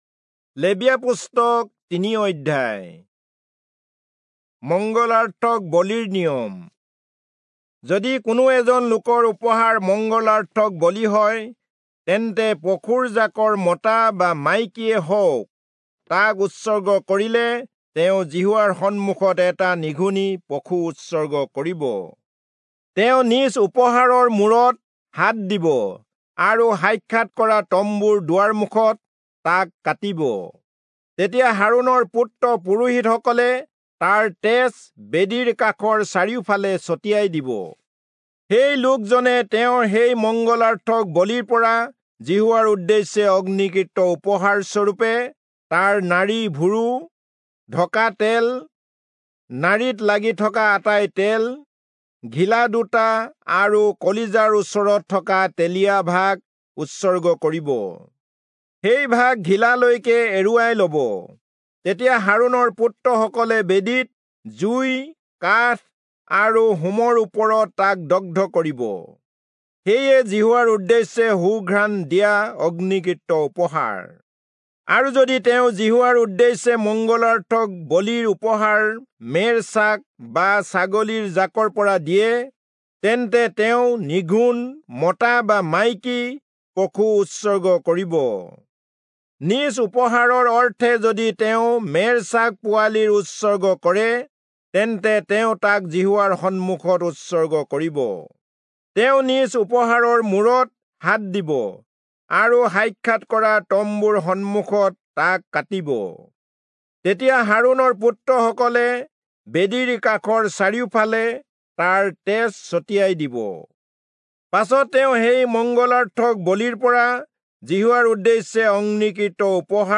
Assamese Audio Bible - Leviticus 24 in Ervgu bible version